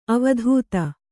♪ avadhūta